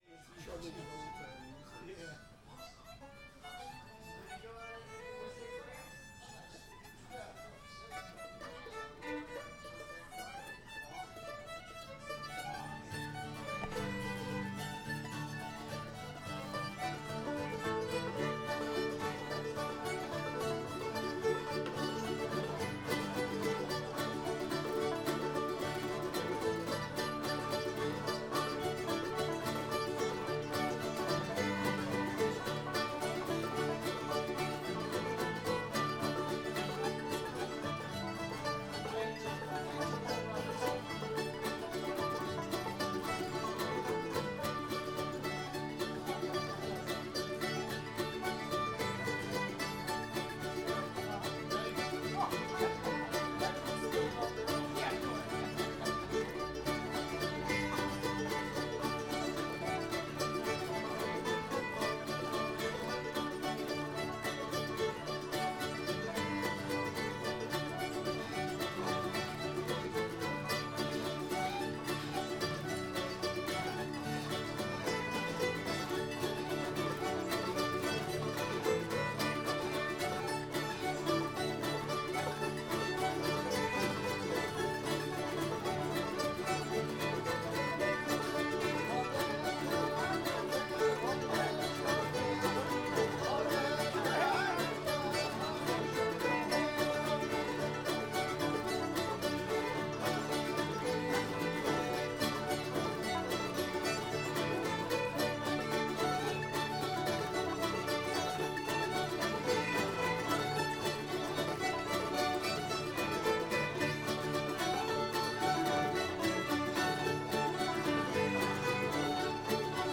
sugar hill [D]